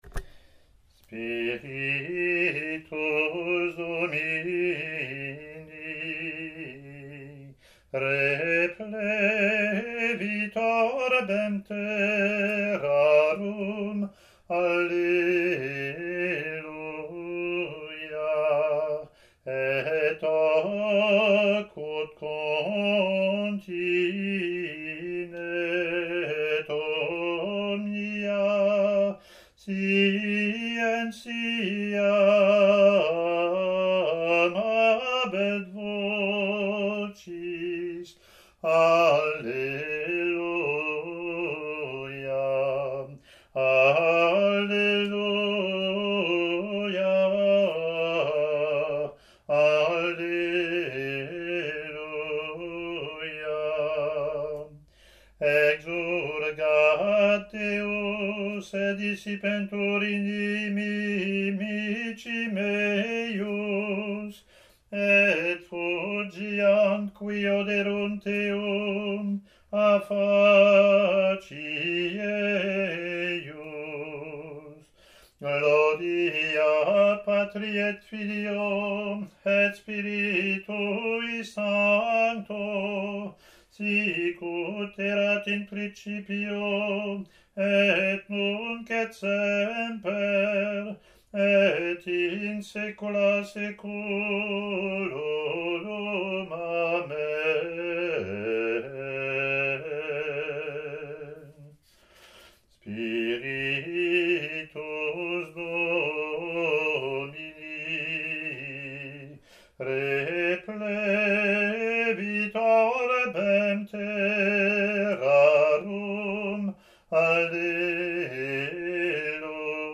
Latin antiphon and verse)